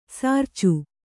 ♪ sārcu